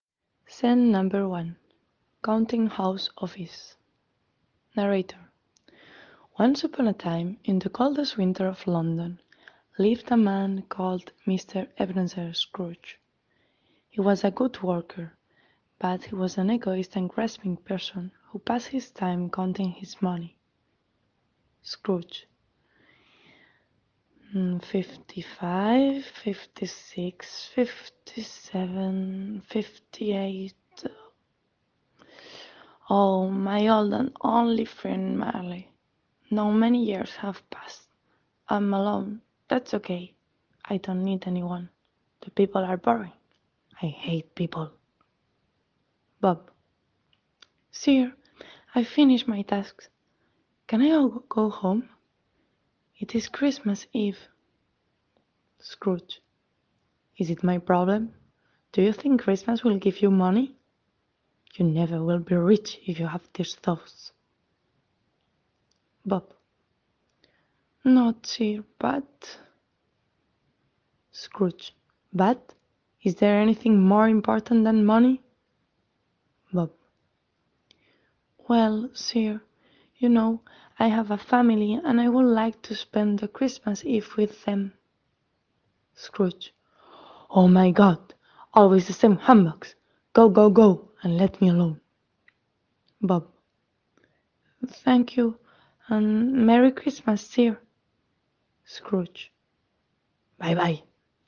There is one very slowly and the other is normal.